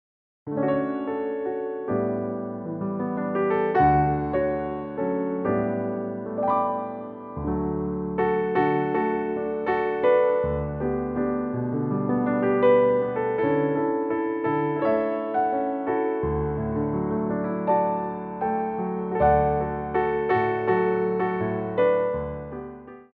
for Ballet Class
Slow Tendus
4/4 (8x8)